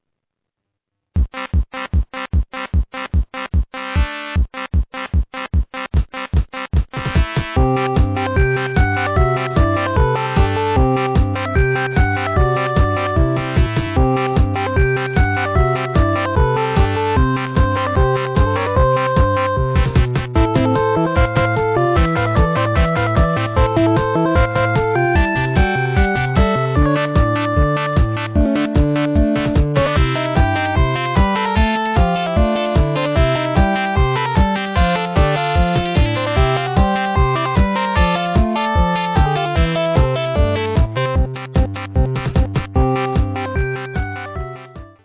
視聴とても軽快。